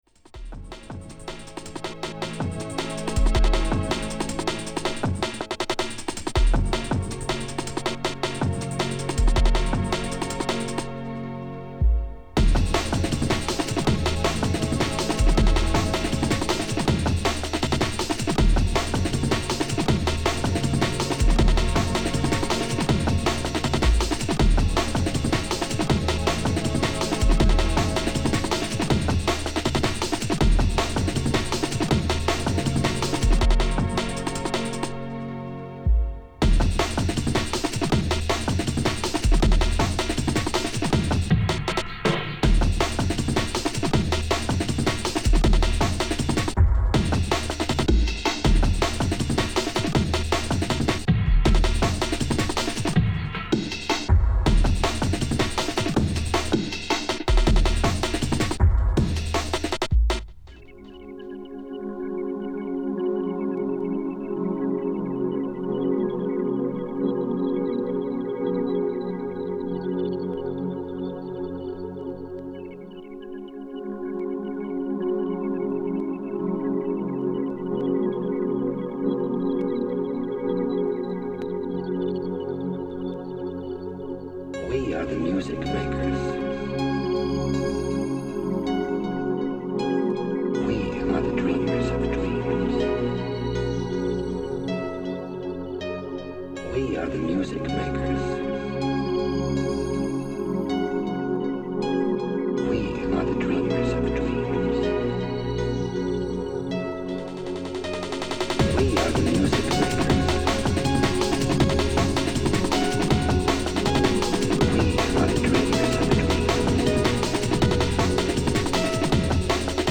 seminal production trio
old school hardcore to UK jungle / drum and bass
All ten tracks have been professionally remastered